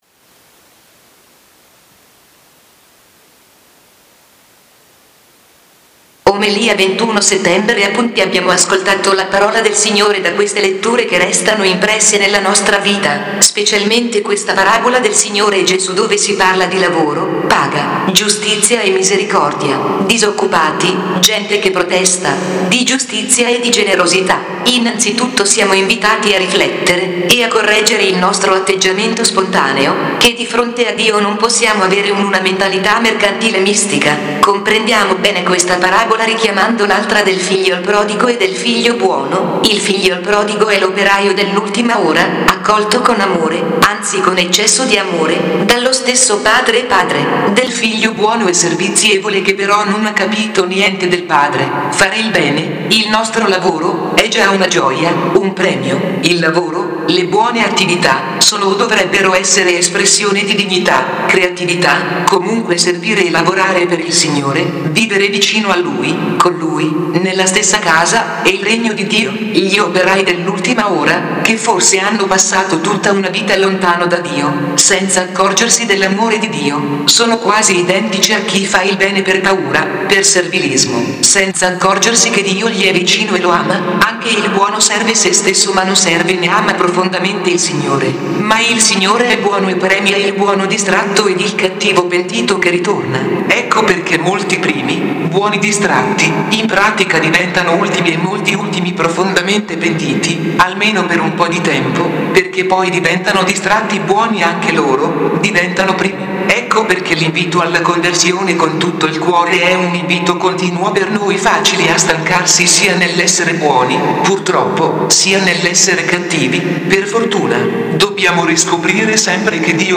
Omelia 21 settembre/ Appunti abbiamo ascoltato la parola del Signore da queste letture che restano impresse nella nostra vita. Specialmente questa parabola del Signore Gesù dove si parla di lavoro, paga, giustizia e misericordia.